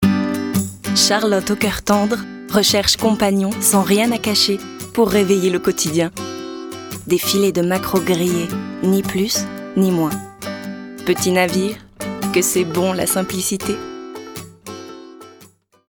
Comédienne
Voix off